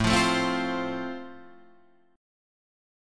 Am#5 chord {5 8 7 5 6 8} chord